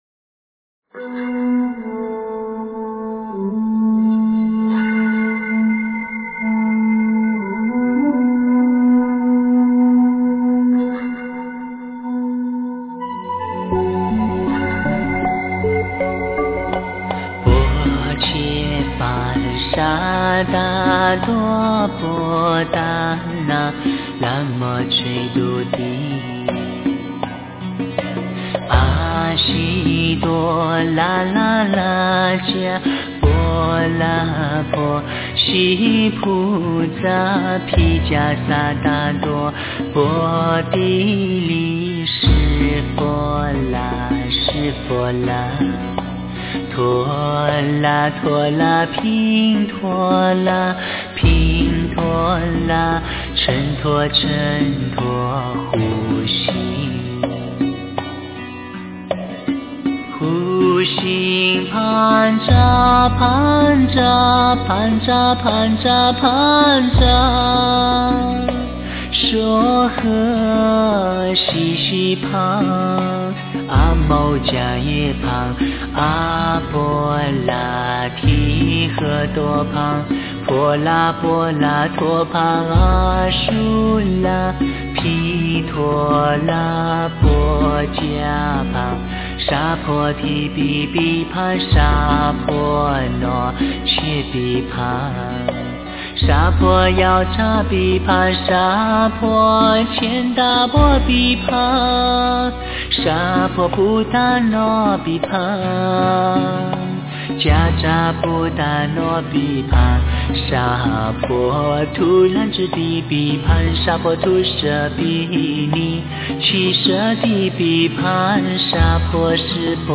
真言
佛教音乐